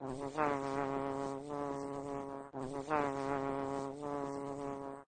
bee_buzz_longer.ogg